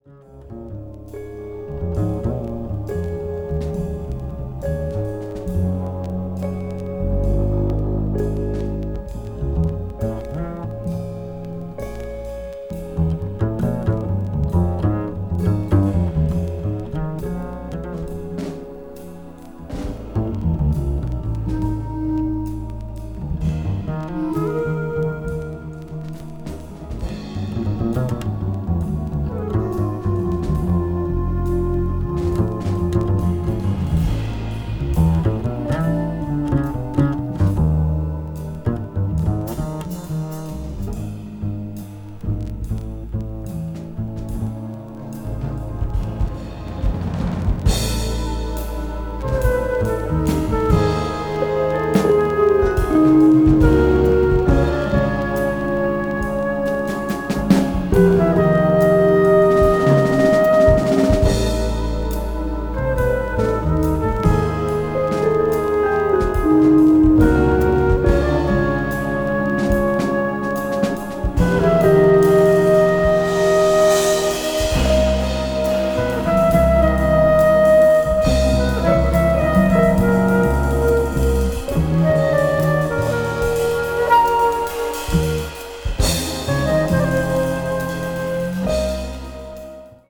avant-jazz   contemporary jazz   crossover